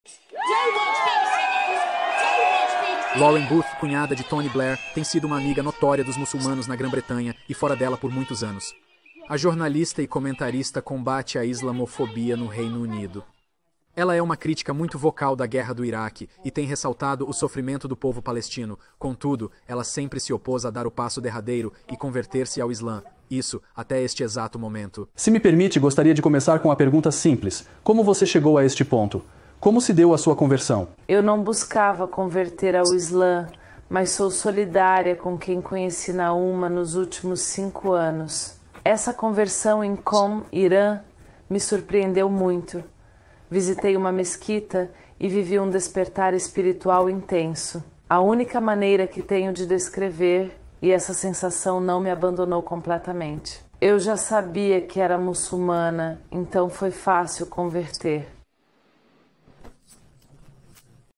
Descrição: Neste vídeo, Lauren Booth, cunhada de Tony Blair, compartilha sua história de conversão ao Islã.